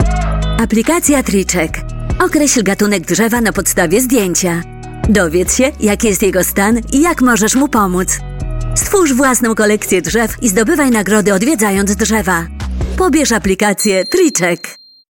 Sprecherin, Schauspielerin
Commercial - Treecheck ° Polnisch